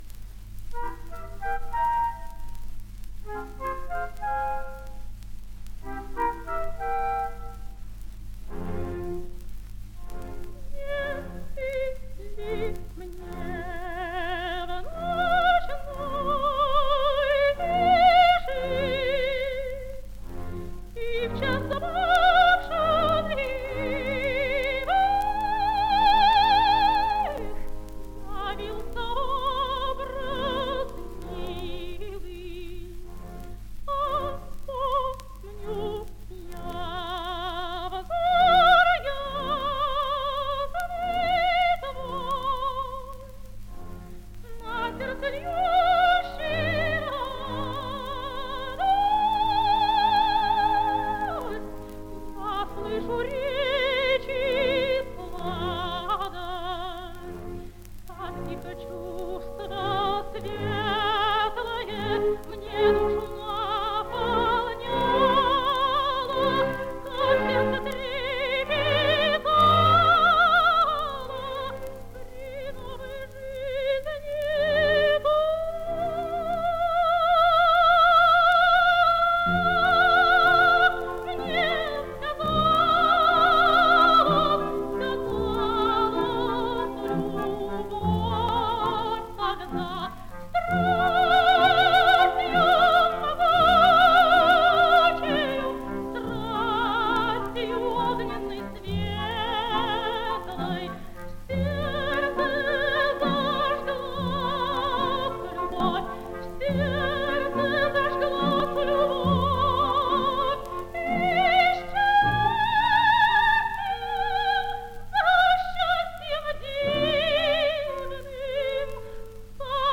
Обладала лирико-колоратурным сопрано красивого серебристого тембра, подвижной вокальной техникой, свободным верхним регистром, тонкой нюансировкой; исполнение отличалось простотой и естественностью.
Опера «Травиата». Ария Виолетты. Оркестр Большого театра.
Исполняет В. М. Фирсова.